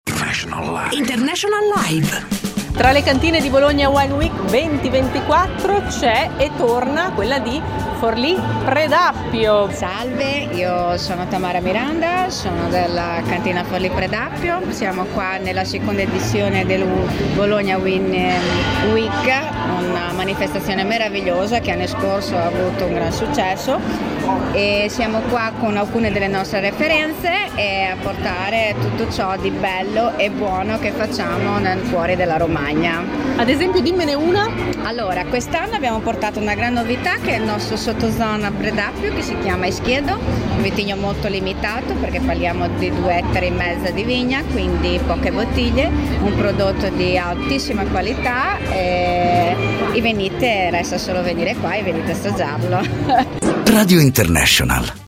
Bologna Wine Week – le interviste
BOLOGNA WINE WEEK - Radio International Live